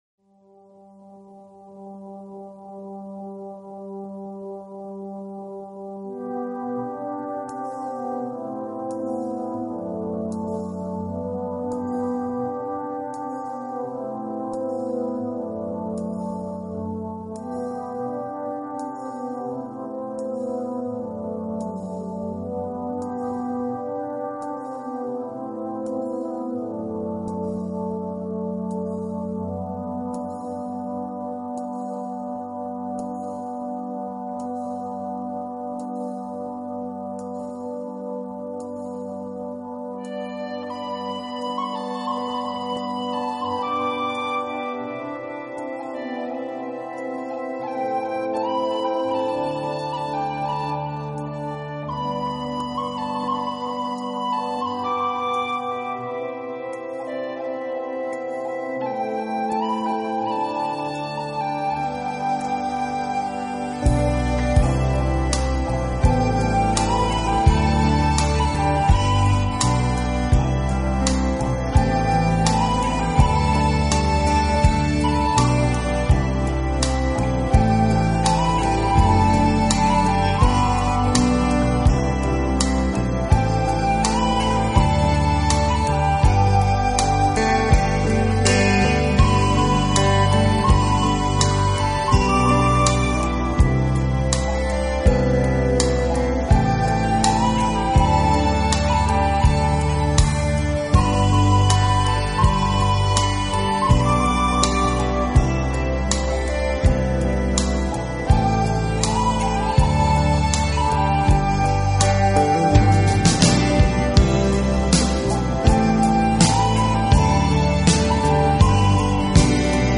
音乐类型: New Age / Celtic